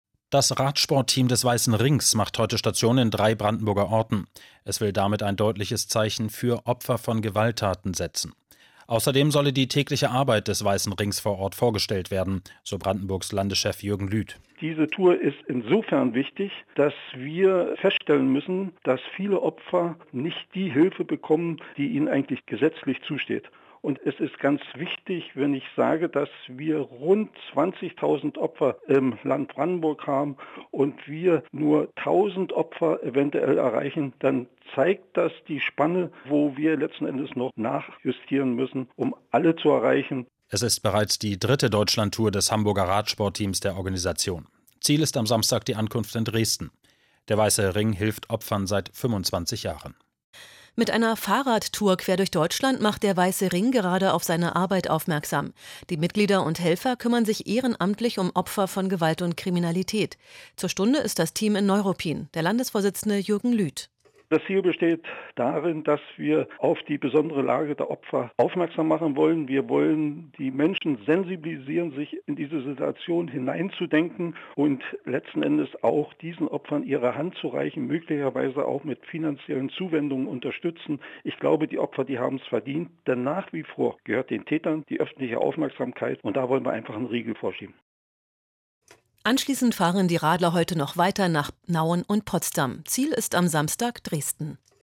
Antenne Brandenburg berichtete in der Nachrichtensendung am 28.8.2018 über die Fahrradtour